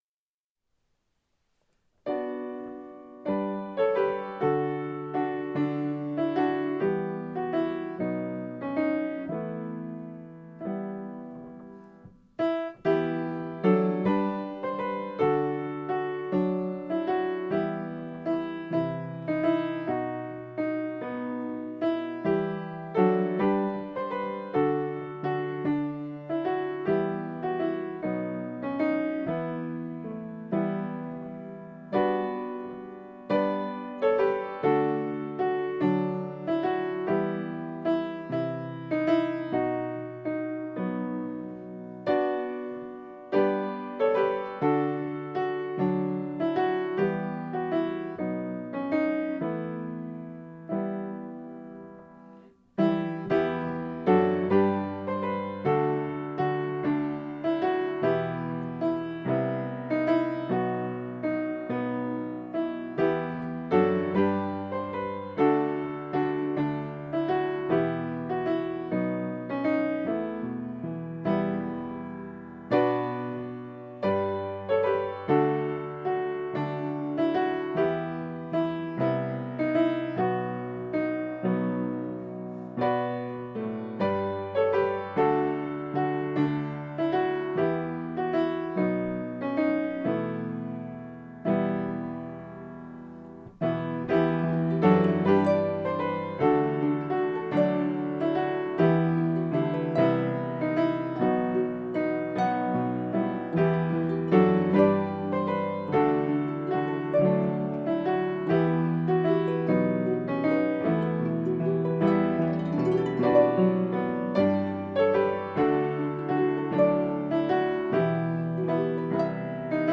Here are Christmas songs for corporate singing.
harp last verse (3 verses: hymnbook key E minor)Download